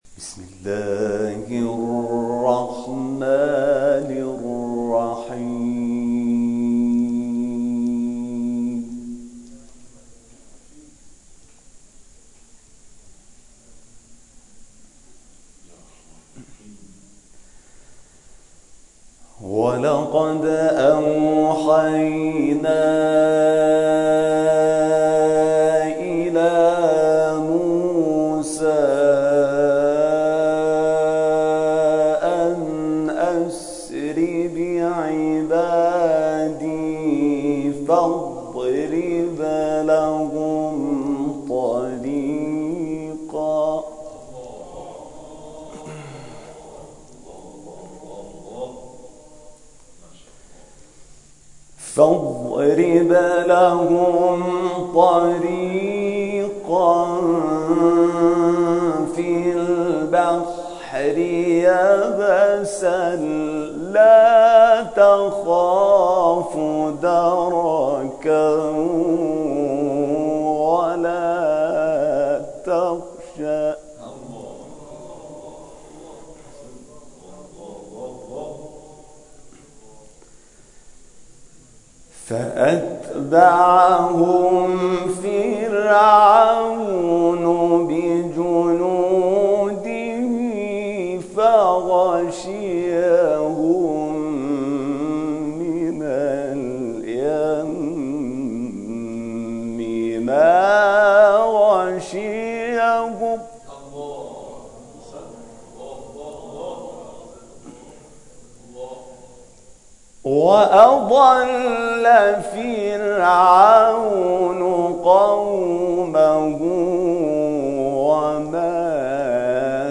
جلسه آموزش قرآن
با حضور قاریان ممتاز در آستان امامزاده پنج تن لویزان برگزار شد.